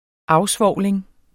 Udtale [ ˈɑwˌsvɒwˀleŋ ]